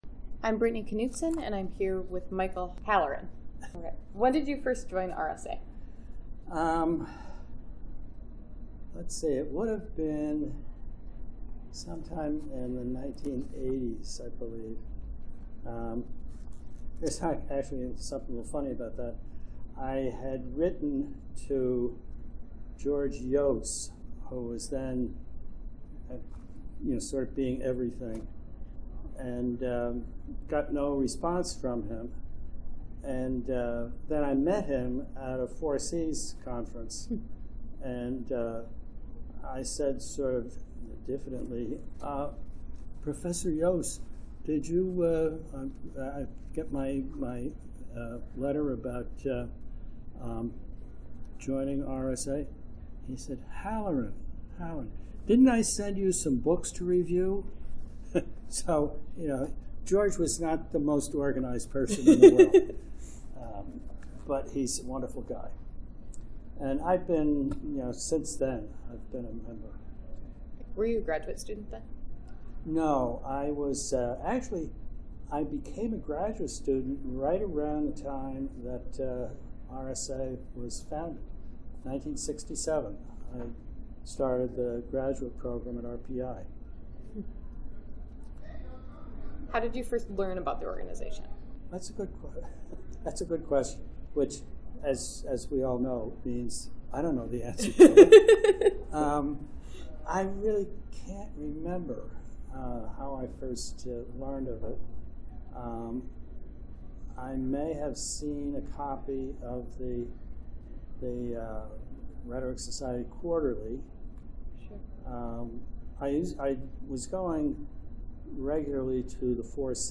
Archival materials from the Rhetoric Society of America's Oral History Initiative.
Oral history interview
Location 2018 RSA Conference in Minneapolis, Minnesota